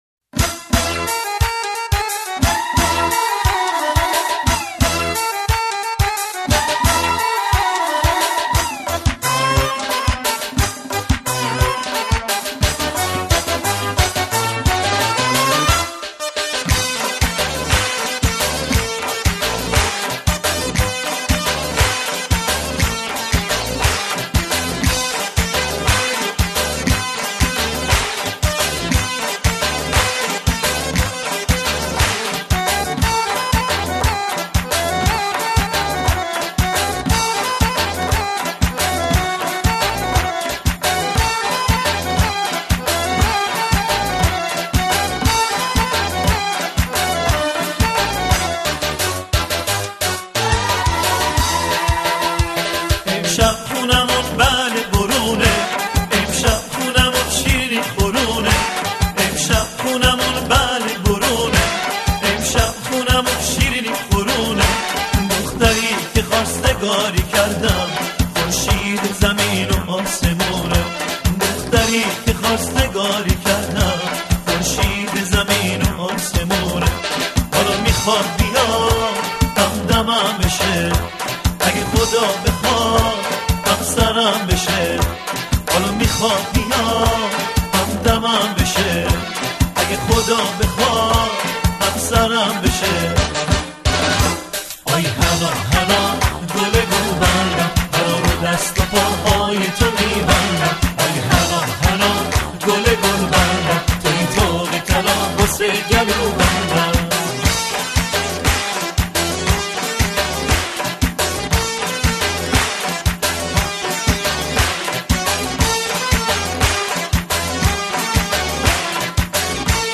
با ریتم شاد و متن دلنشین خود